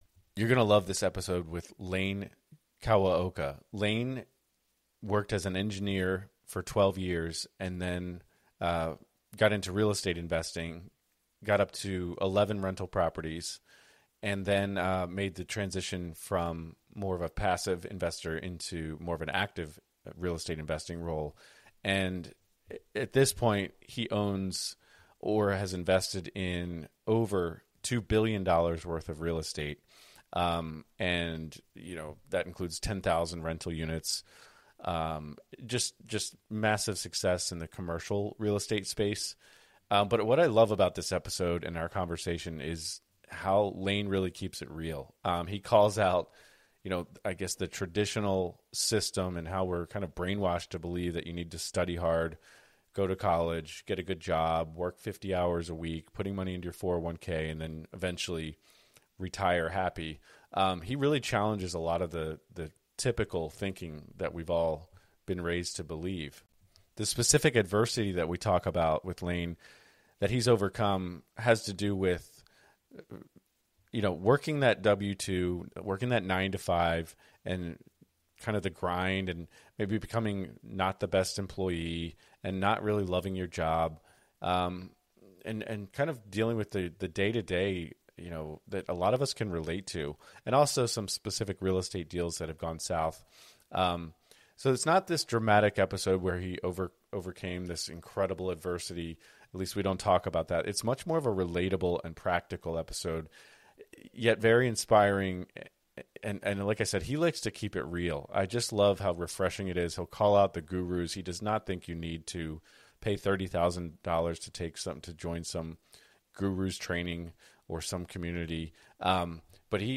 Each week, join us as we dive into the compelling world of real estate through the lens of mental fitness, where challenges transform into opportunities. Our show brings you riveting, interview-based stories from seasoned professionals and inspiring newcomers alike, each sharing their unique journey from struggle to success in